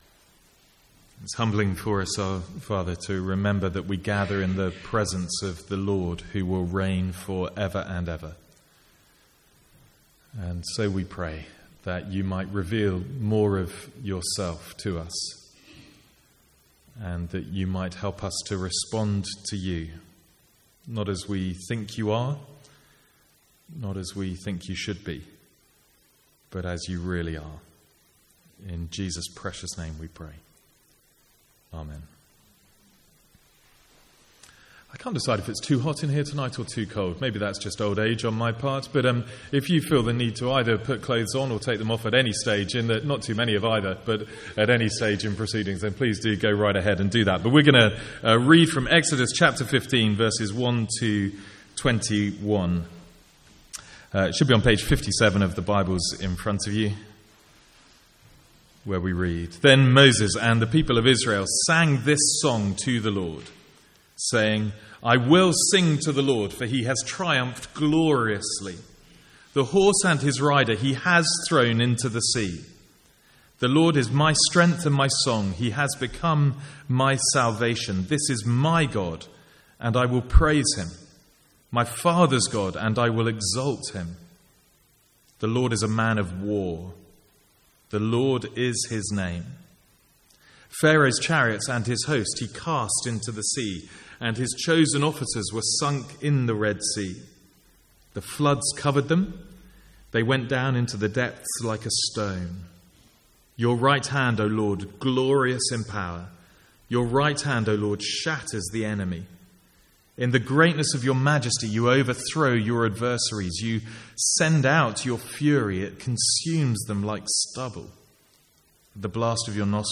Sermons | St Andrews Free Church
From the Sunday evening series in Exodus.